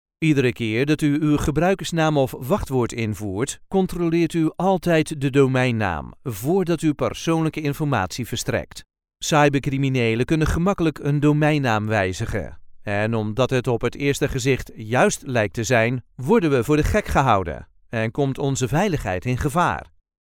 Grabaciones en nuestro estudio de sonido asociado de Holanda.
Locutores holandeses